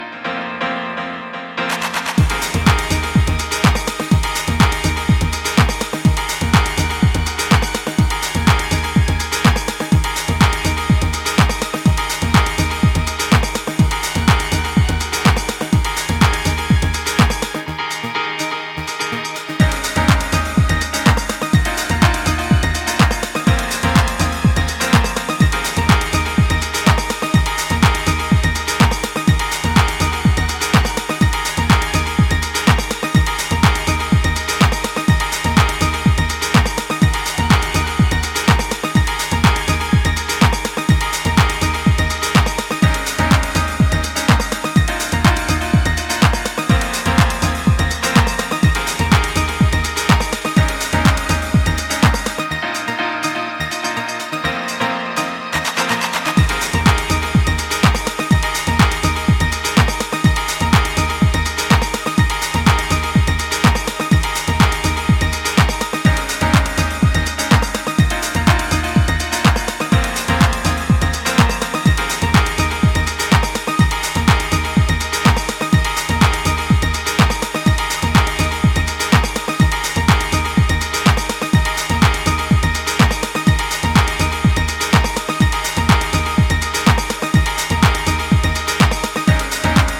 techno and house
is a straight club killer, 90's house affair.
with jacking 909 drums and uplifting old school piano hits.